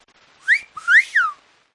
Tesla Lock Sound Cartoon – Whistle
Cartoon Whistle sound
(This is a lofi preview version. The downloadable version will be in full quality)
JM_Tesla_Lock-Sound_Cartoon-Whistle_Watermark.mp3